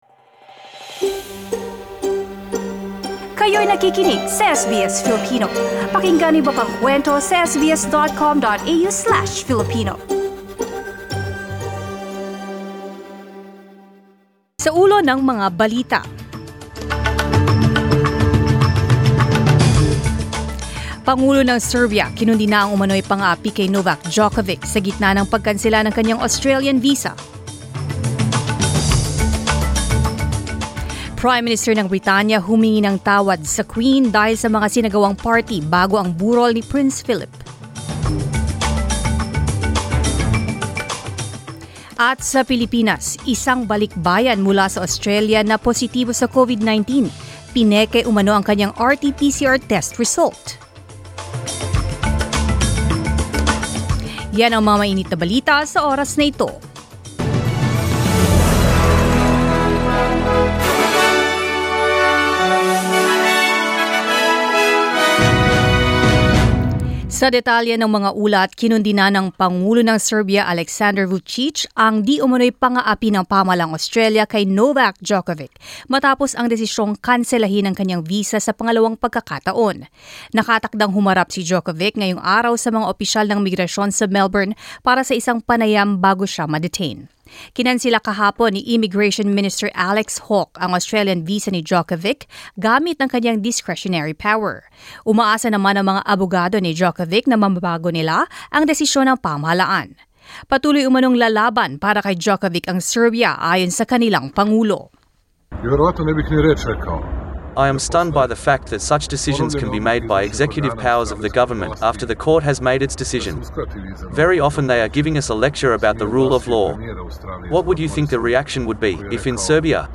Mga balita ngayong ika-15 ng Enero